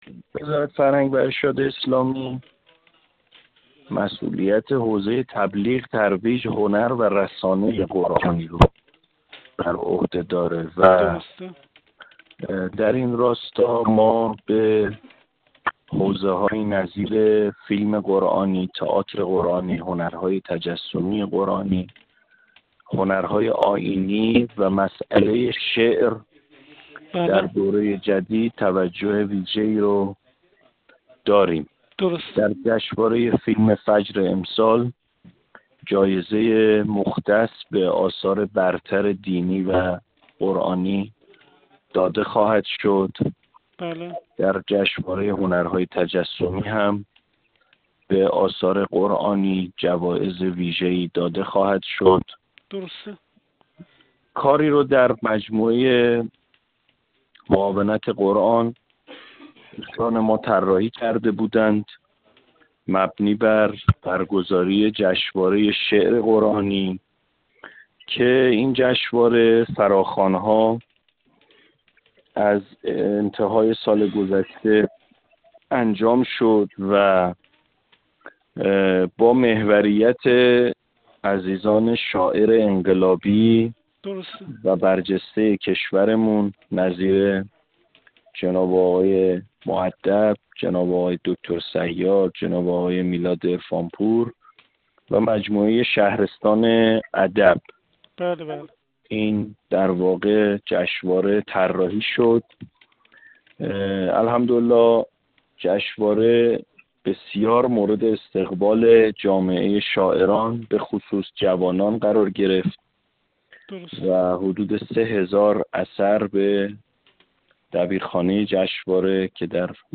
علیرضا معاف، معاون قرآن و عترت وزیر فرهنگ و ارشاد اسلامی در گفت‌وگو با خبرنگار ایکنا، با اشاره به اینکه این وزارتخانه مسئولیت حوزه تبلیغ، ترویج، هنر و رسانه قرآنی را بر عهده دارد، گفت: در همین راستا در دوره جدید به حوزه‌هایی نظیر فیلم قرآنی، تئاتر قرآنی، هنرهای تجسمی قرآنی، هنرهای آئینی و مسئله شعر توجه ویژه‌ای خواهیم داشت.